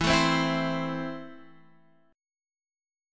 Esus4#5 chord